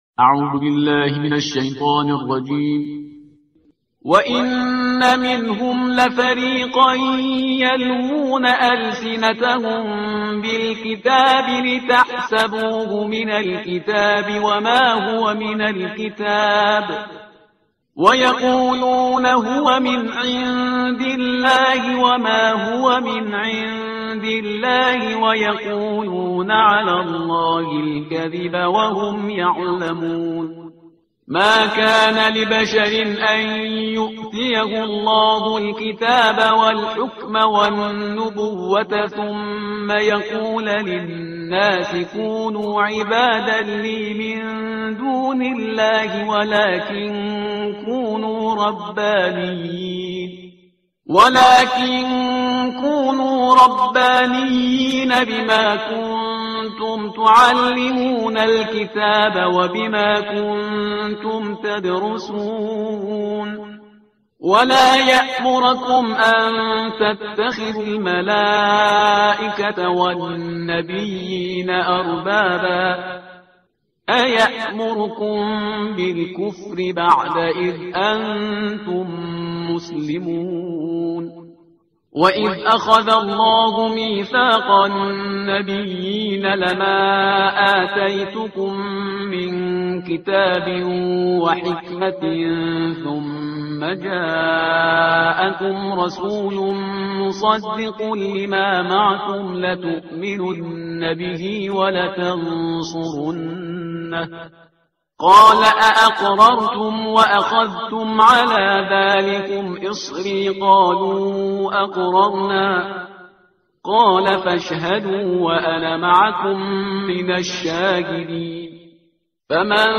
ترتیل صفحه 60 قرآن با صدای شهریار پرهیزگار